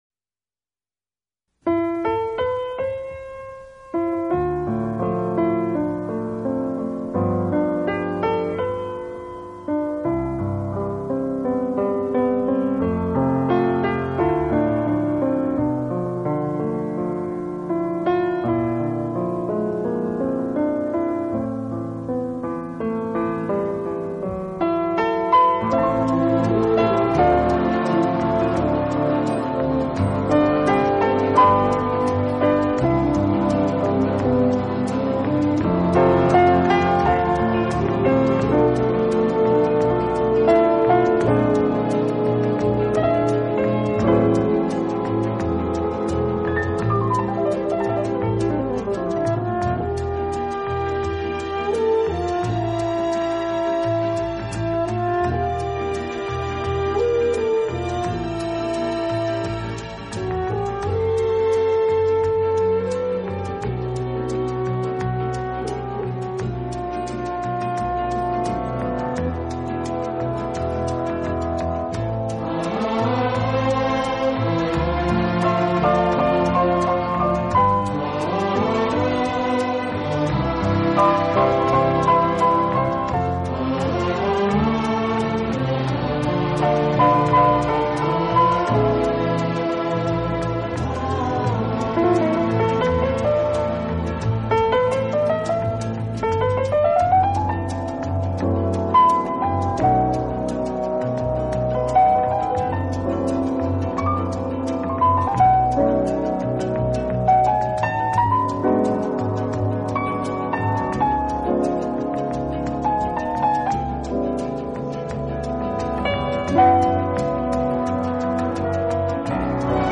专辑中除了钢琴，还有人声伴唱，充满了怀旧的 情愫，是不可多得的音乐资料。